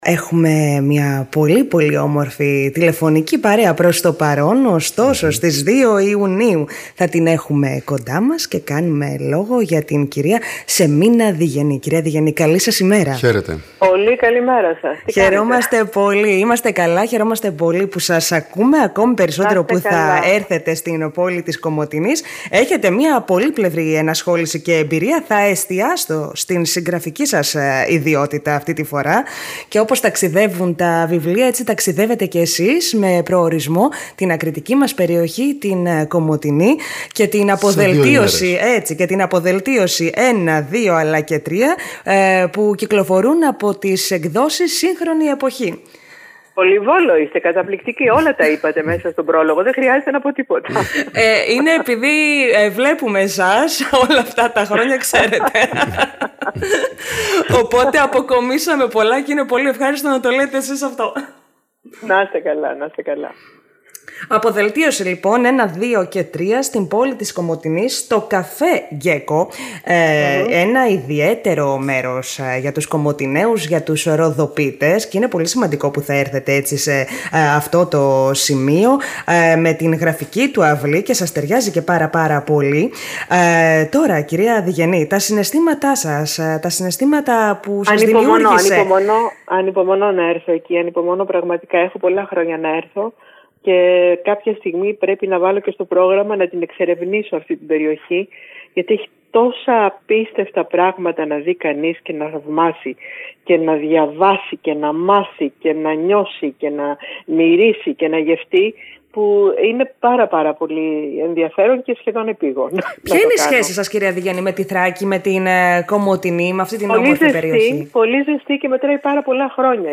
Η συνέντευξη της Σεμίνας Διγενή στην ΕΡΤ Κομοτηνής